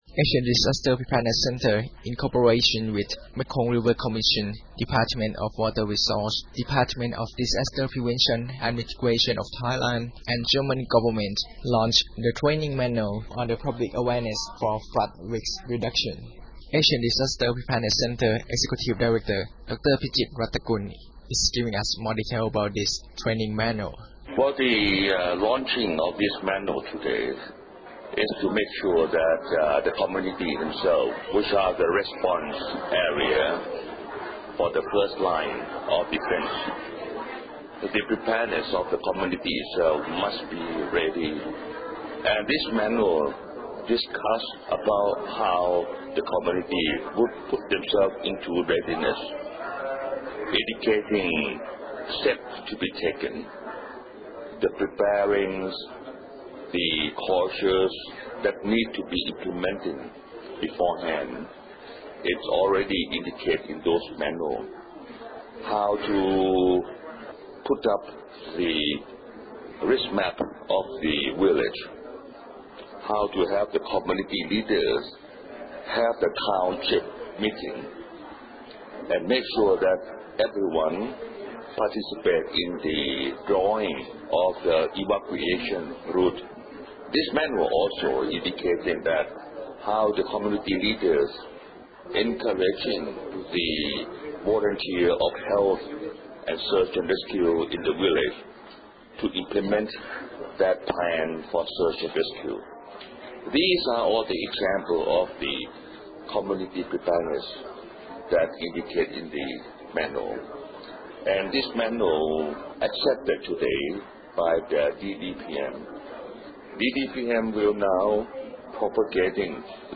Dr. Bhichit Rattakul, ADPC Executive Director, talked to Radio Thailand at the National Consultation Meeting on Public Awareness for Flood Risk Reduction in Thailand about the objective of the meeting and the importance of raising public awareness on flood prevention and response. This recorded interview has been aired through FM. 88
Interview_Radio Thailand_3Sept.mp3